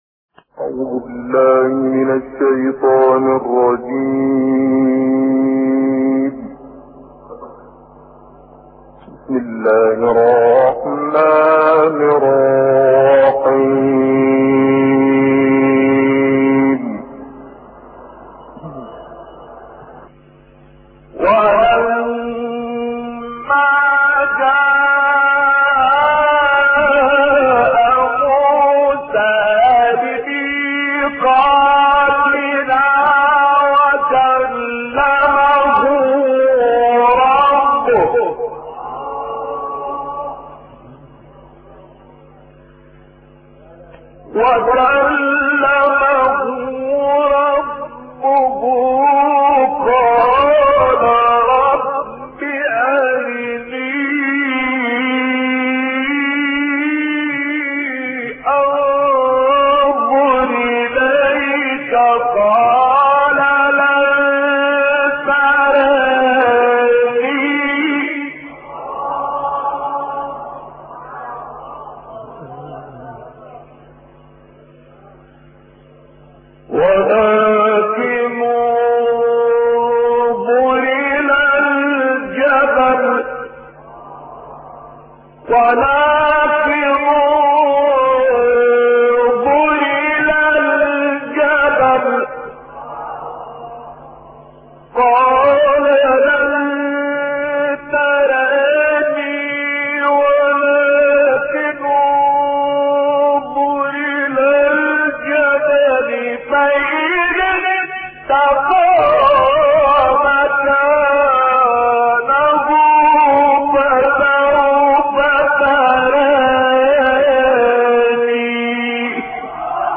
Quran recitations